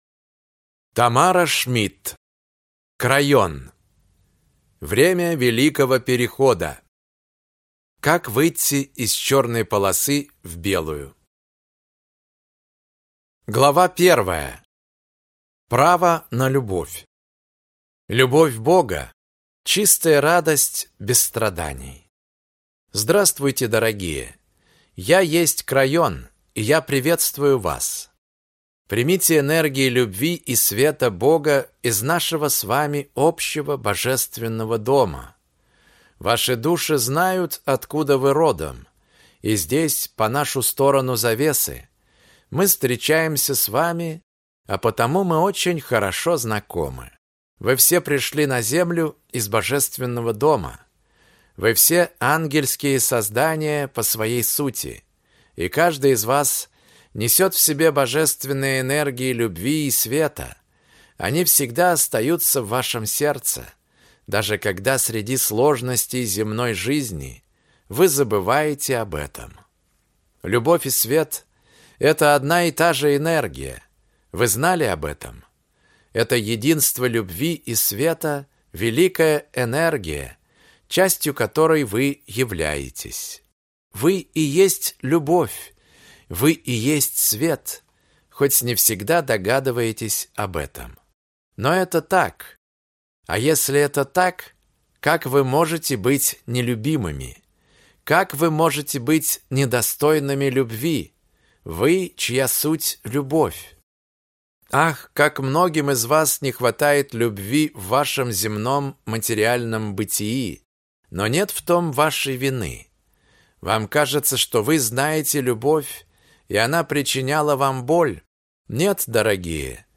Аудиокнига Крайон. Время Великого Перехода. Как выйти из черной полосы в белую | Библиотека аудиокниг